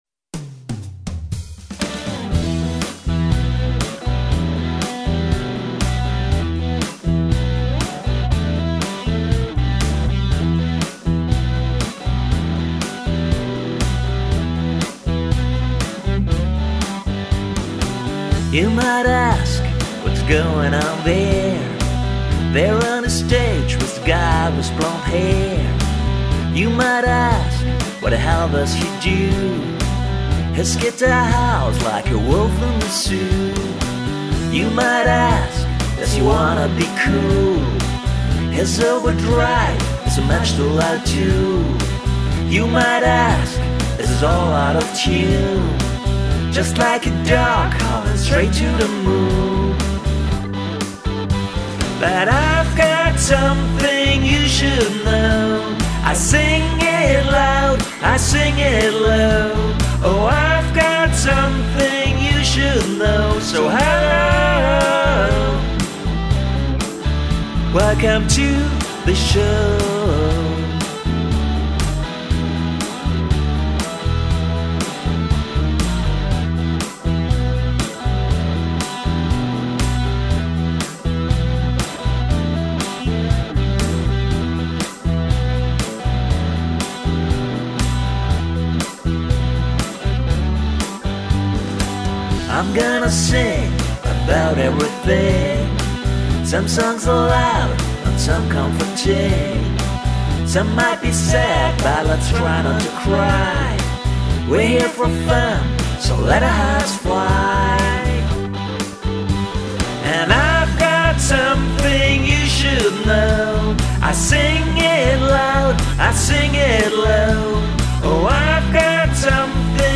vocals, electric guitars, bass, drums